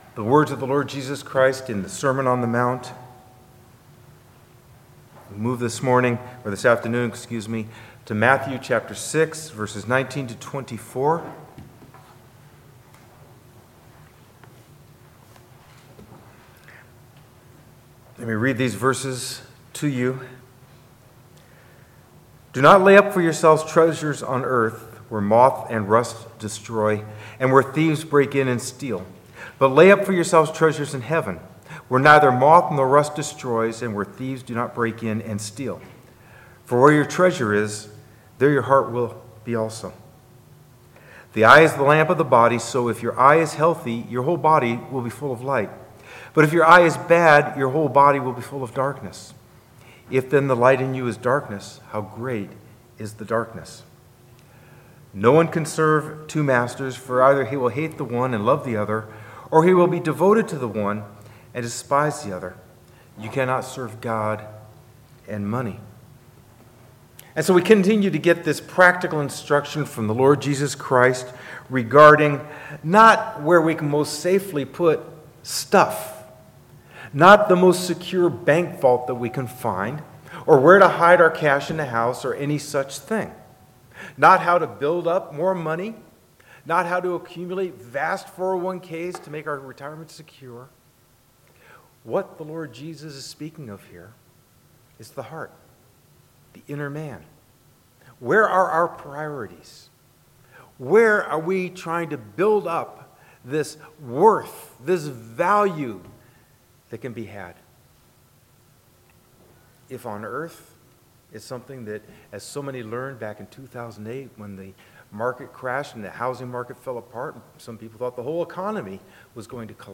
Curious about something taught in this sermon?